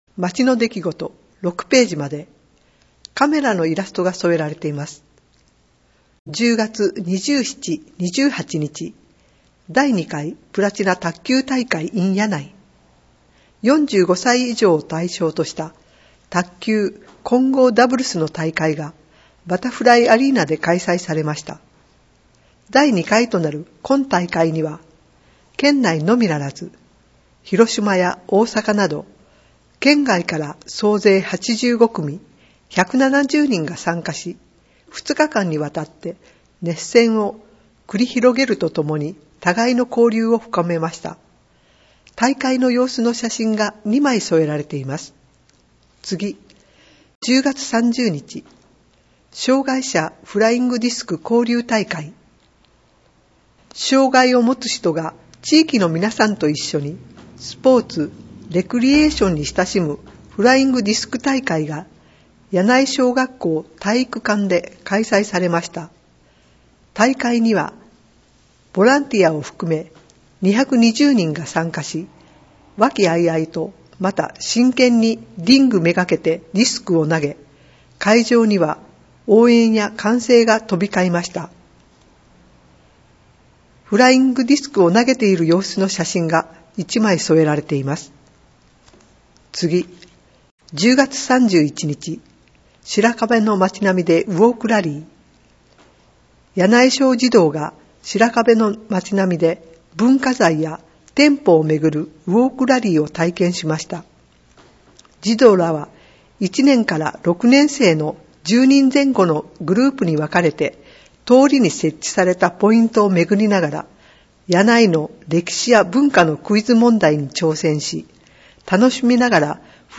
広報やない平成28年11月24日号 [PDFファイル／4.23MB] 平成28年度柳井市功労者表彰／市政だより [PDFファイル／1.84MB] 音声ファイル [5.49MB] まちの出来事／市長コラム／12月の子育て支援 [PDFファイル／2.87MB] 音声ファイル [5.5MB] 音訳版デイジー図書広報はこちらからダウンロードできます [10.74MB] 「音訳しらかべの会」の皆さんによる声の広報（音訳版広報）を、発行後1週間程度で掲載しています。